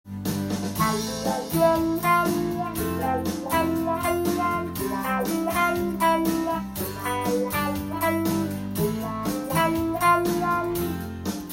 名前の通り「ワウワウ」した音が出ます。
伴奏で使うワウペダル
歌の伴奏や誰かがメロディーを弾いている時に使うと効果的です。